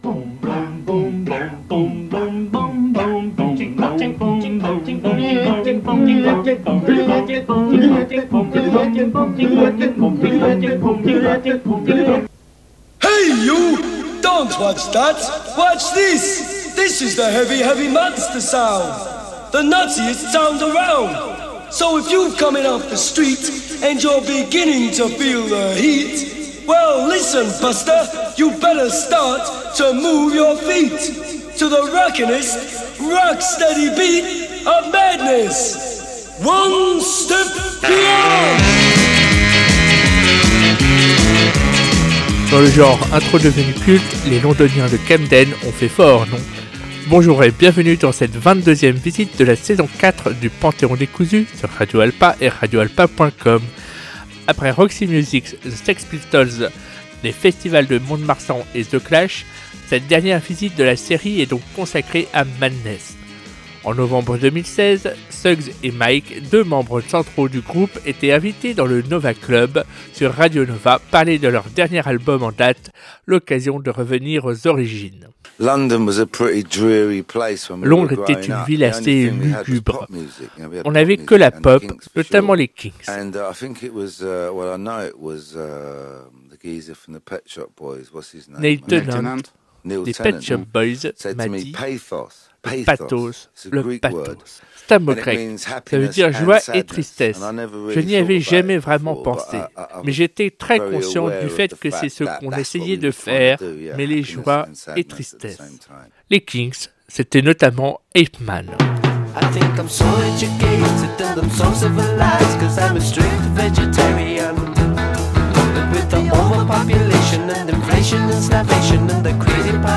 Et comme toujours : du son culte, du son rare et des surprises !